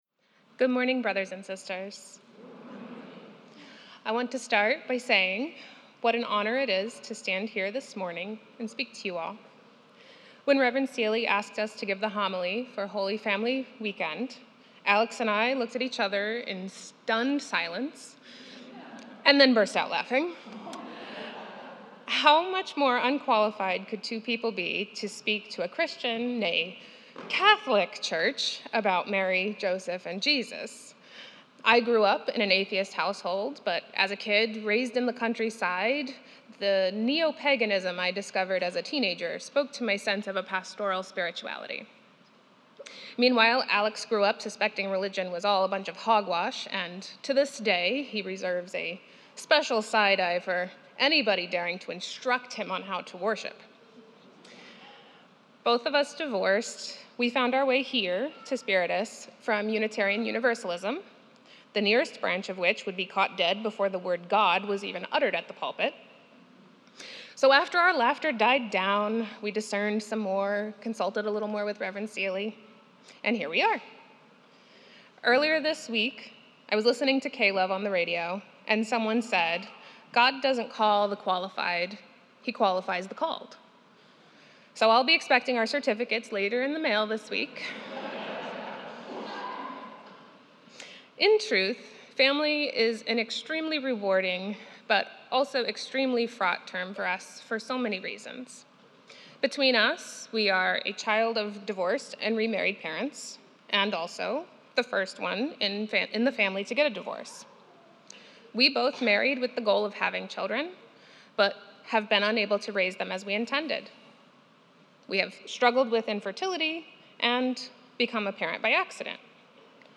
Holy Family Liturgy 2024